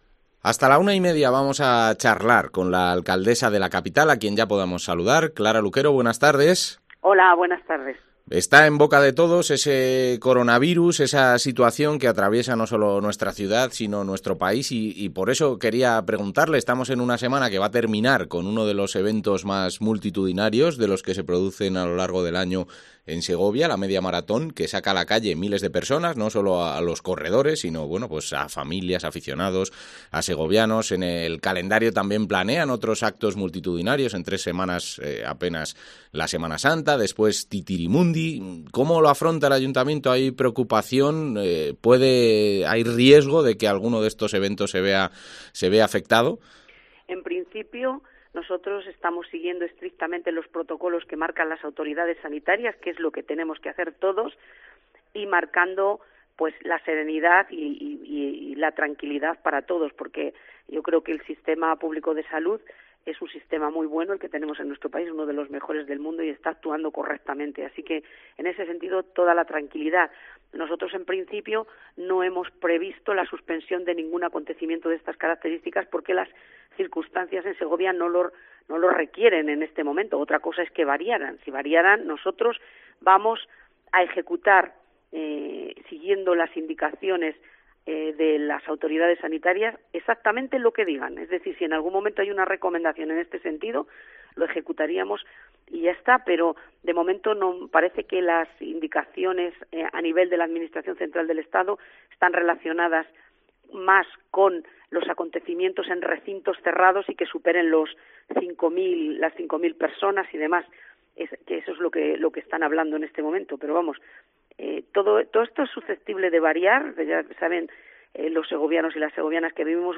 Entrevista a Clara Luquero